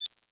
sfx_interface_lockon_02.wav